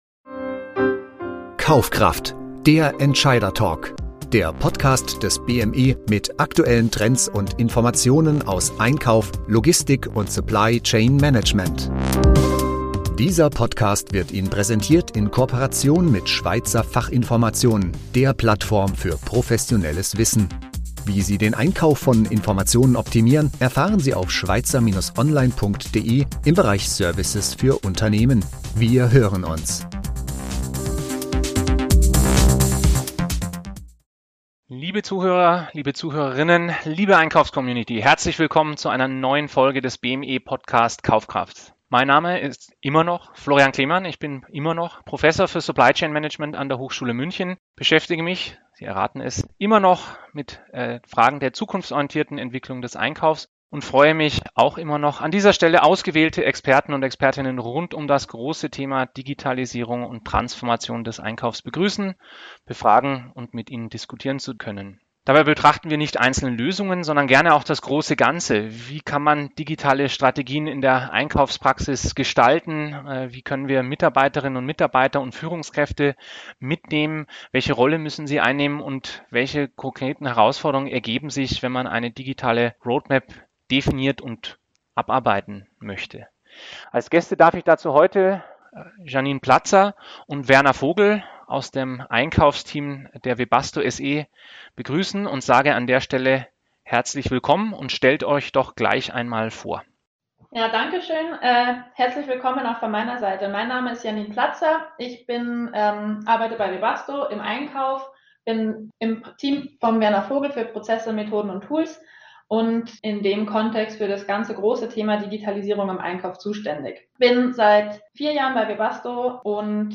Doch damit nicht genug: Im Interview wird auch die Nachhaltigkeit, mit ihren sozialen, ökonomischen und ökologischen Aspekten, als Bezugspunkt und Ausblick thematisiert.